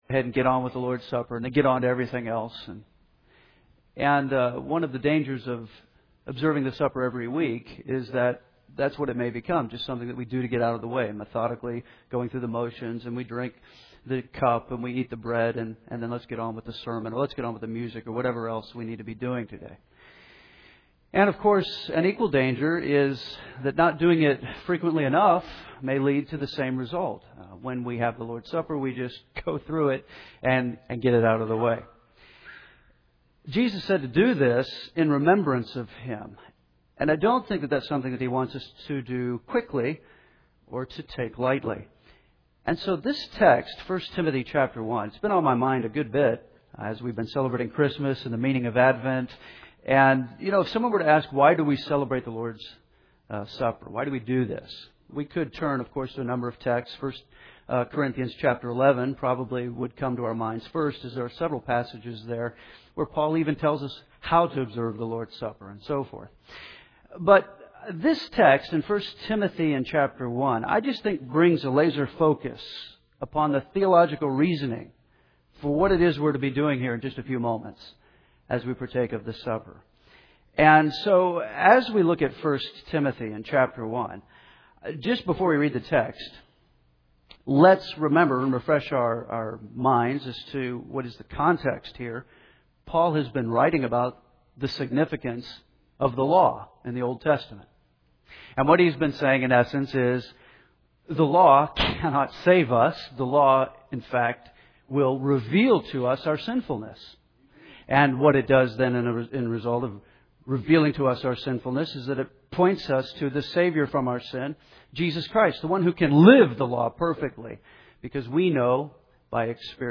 (1 Timothy 1:15) Lord’s Supper Message (1-2-11) Take God’s Word and open to 1 Timothy, chapter 1.